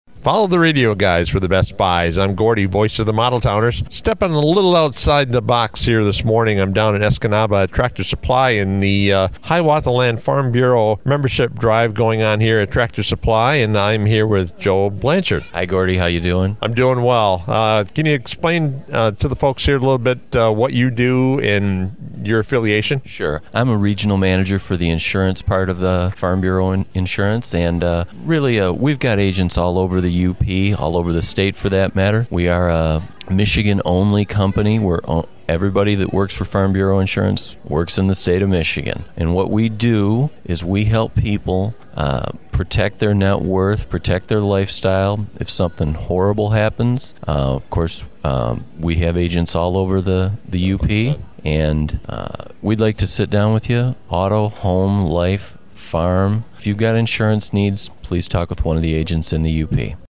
It looked to be a hectic day, as more and more people flooded into the Escanaba Tractor Supply Company’s showroom!